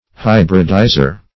Hybridizer \Hy"brid*i`zer\, n. One who hybridizes.